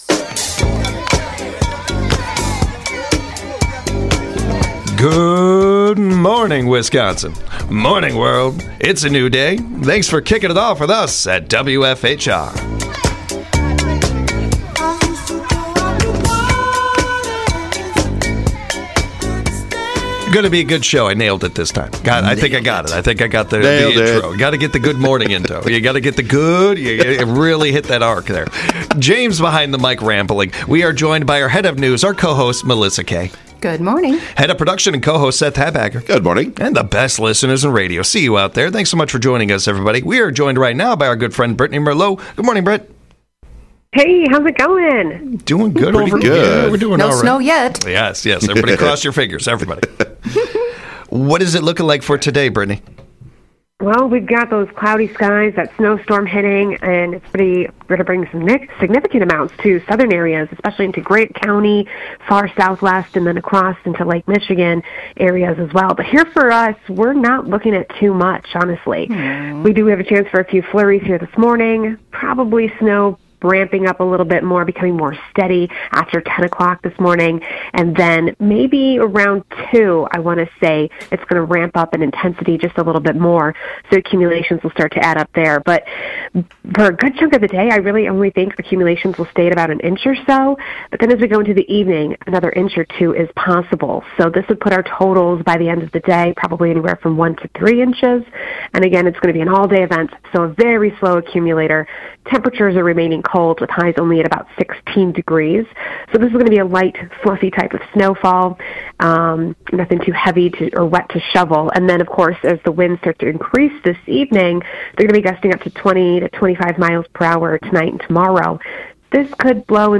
in the studio today